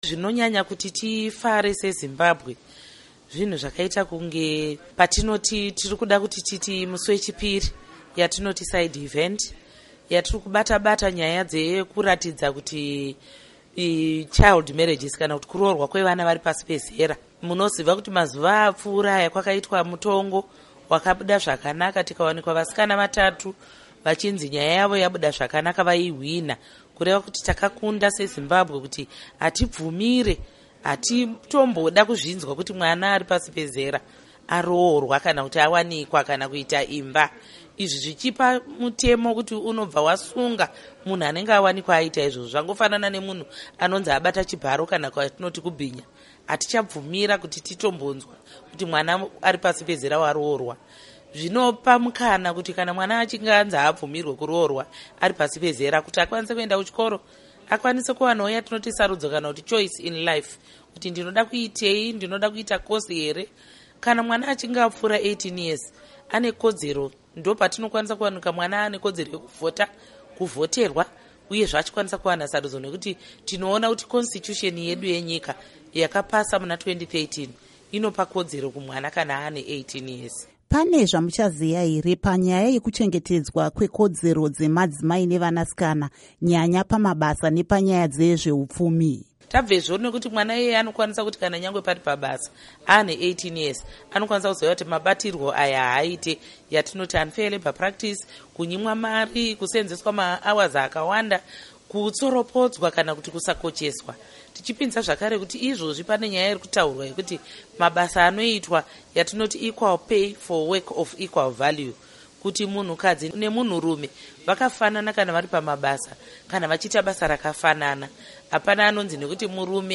Hurukuro naAmai Paurina Mpariwa Gwanyanya